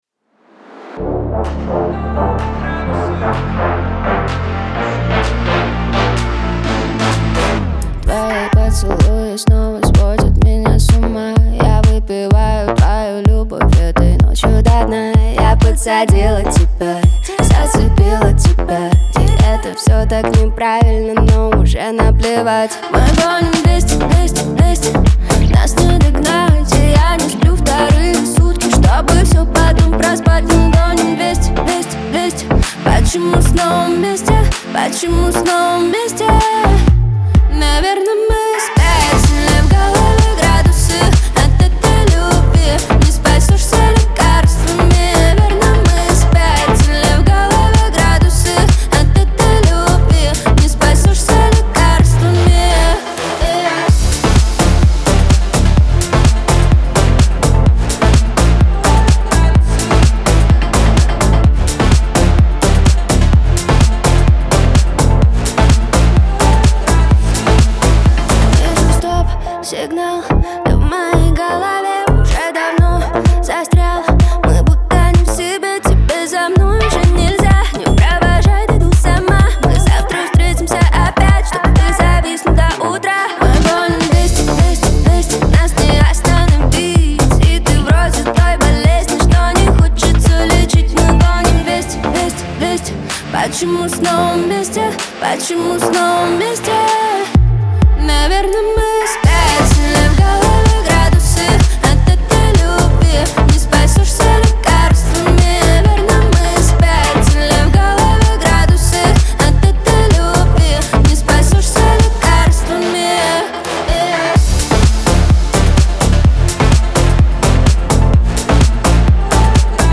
Лирика
pop